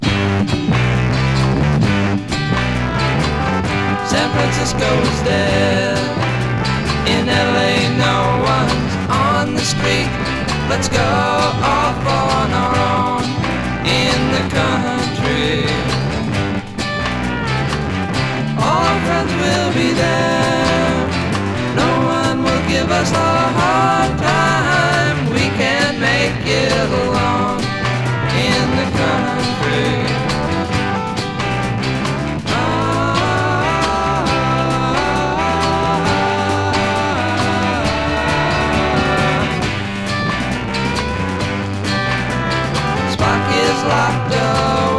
Rock, Psychedelic Rock　USA　12inchレコード　33rpm　Stereo